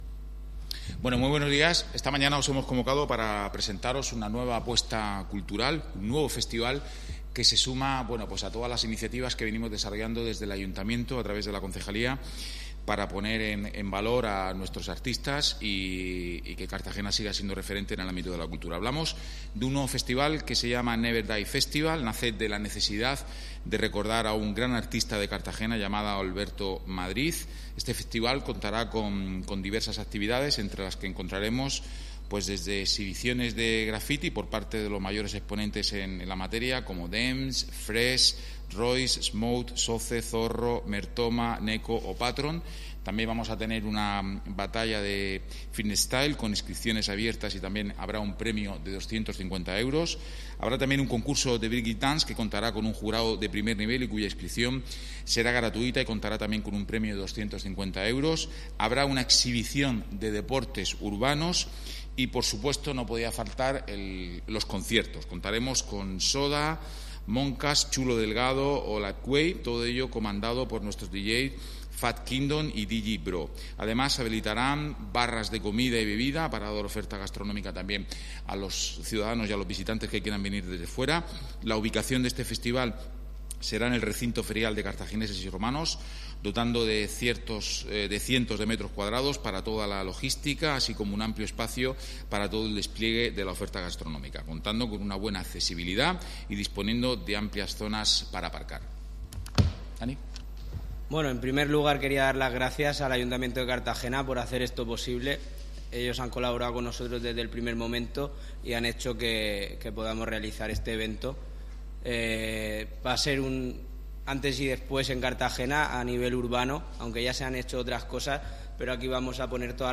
Enlace a Presentación del Festival de Cultura Urbana